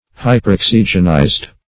Search Result for " hyperoxygenized" : The Collaborative International Dictionary of English v.0.48: Hyperoxygenated \Hy`per*ox"y*gen*a`ted\, Hyperoxygenized \Hy`per*ox"y*gen*ized\, a. (Chem.)
hyperoxygenized.mp3